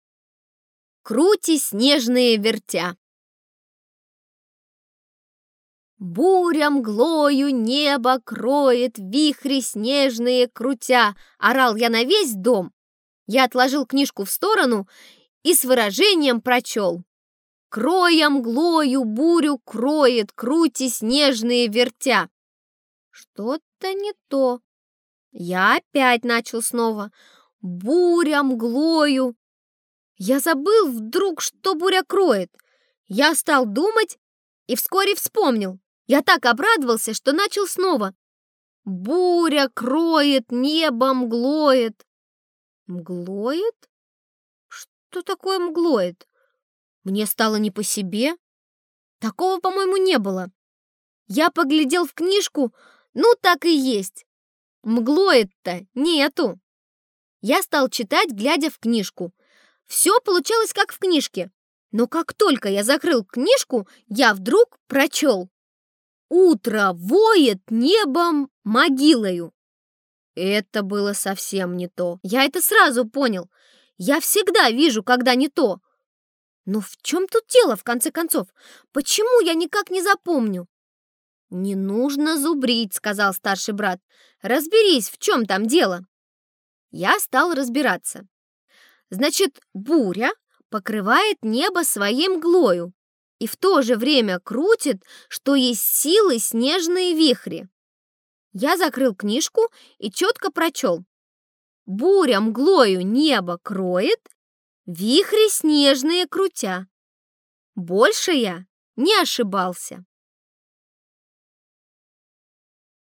Аудиорассказ «Крути снежные вертя»